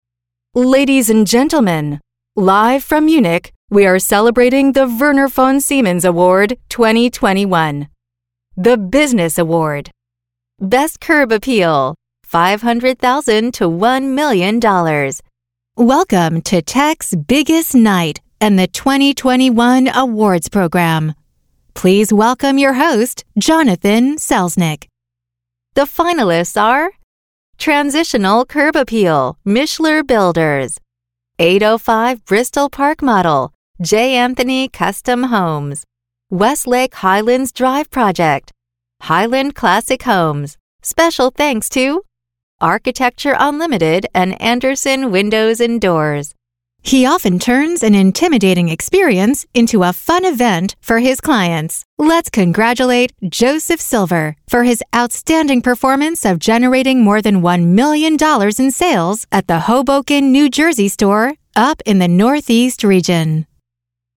Live Announce - Female Voice of God (VOG)
Pre-recorded or live, in-person voiceover talent for your event.
Awards Ceremony